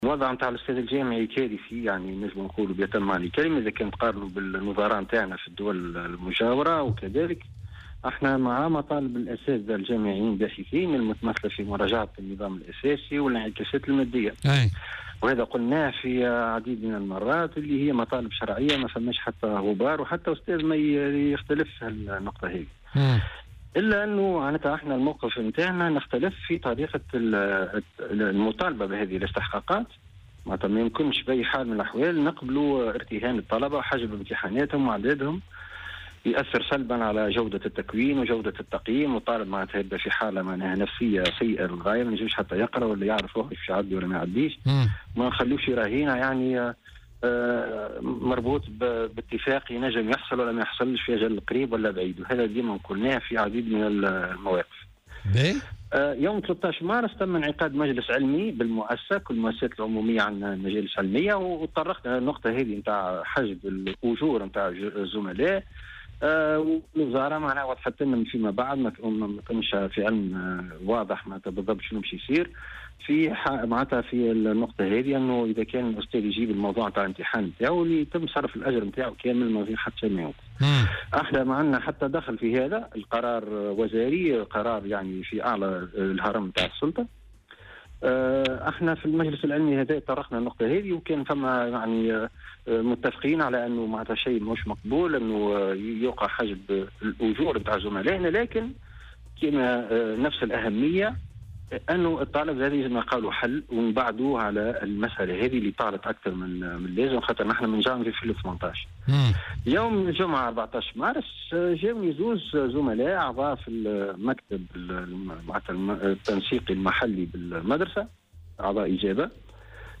وأضاف في مداخلة له اليوم في برنامج "بوليتيكا" أنه أعلم الجهات الرسمية بما حصل و طالب بتوفير حماية خاصة له وذلك بعد أن أجبراه على الاستقالة من منصبه قبل تاريخ 25 مارس الحالي، مستعملين في ذلك أسلوب الترهيب، وفق تعبيره.